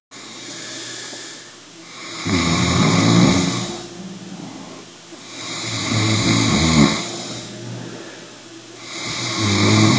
Звук храпа 2
snoring2.wav